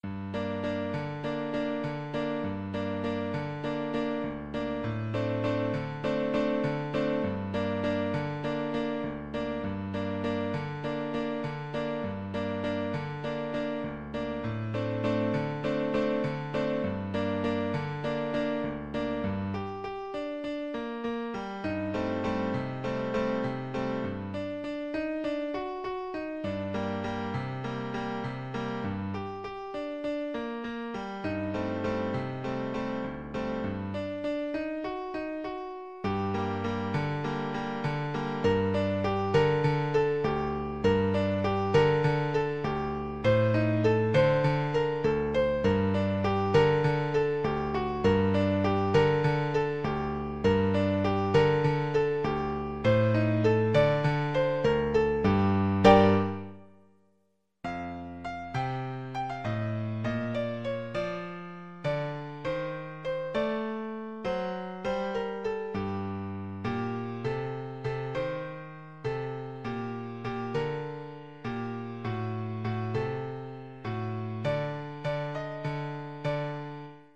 A sultry and atmospheric piece.
Moderato
Arrangement for Piano
World (View more World Piano Music)